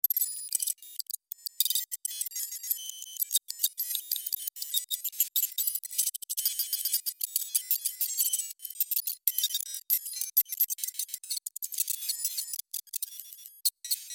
Звук программирования искусственным интеллектом